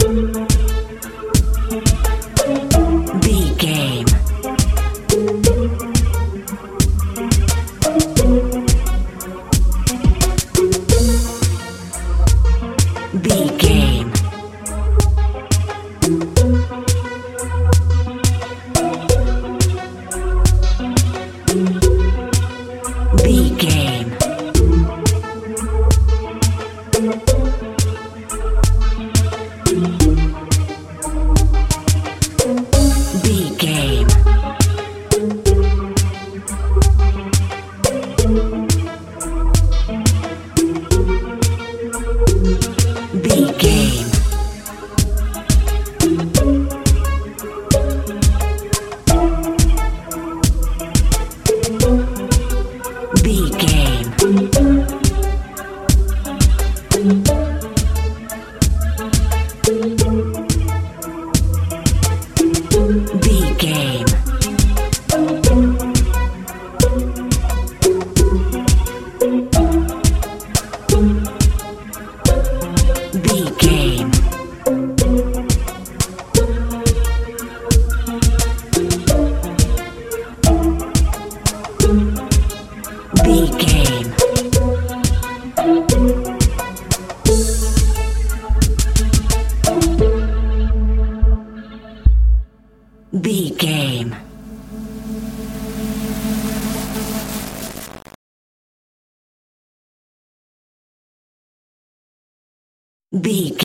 modern pop feel
Ionian/Major
A♭
light
mellow
synthesiser
bass guitar
drums
80s
90s